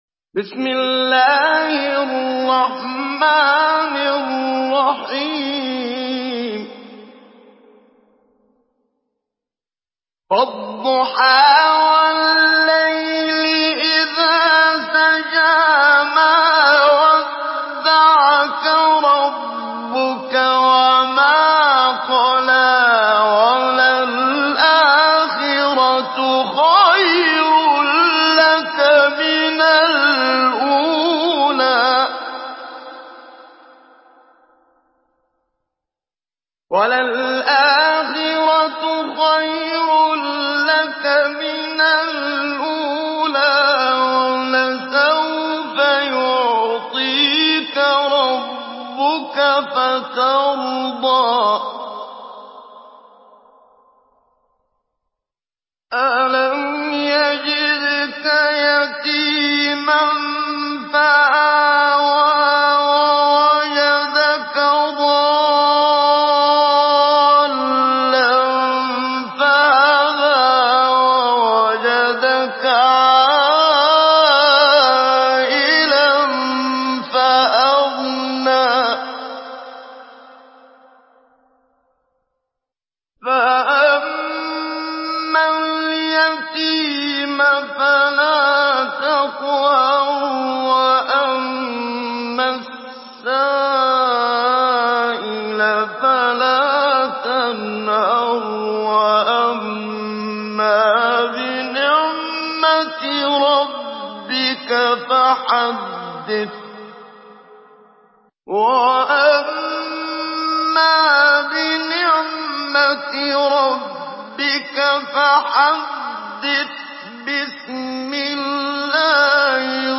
Surah Ad-Duhaa MP3 in the Voice of Muhammad Siddiq Minshawi Mujawwad in Hafs Narration
Surah Ad-Duhaa MP3 by Muhammad Siddiq Minshawi Mujawwad in Hafs An Asim narration.